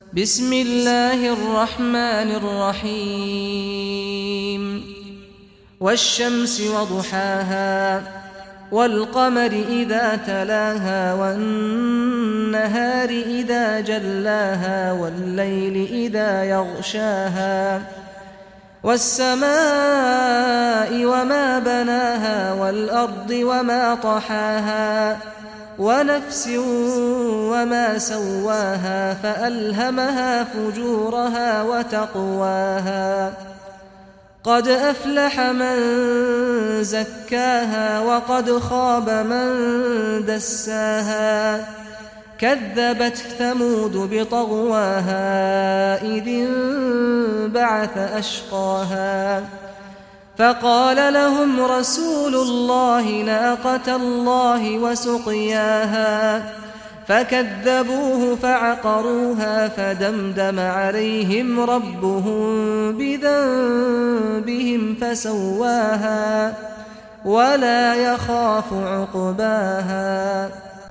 Surah Ash-Shams Recitation by Sheikh Saad Ghamdi
Surah Ash-Shams, listen or play online mp3 tilawat / recitation in Arabic in the beautiful voice of Sheikh Saad al Ghamdi.